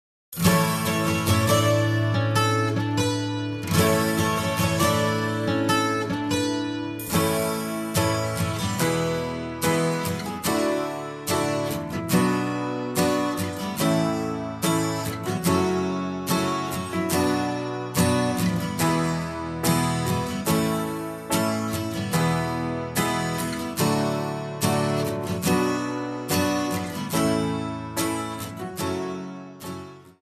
Backing track files: Duets (309)
(no vocals)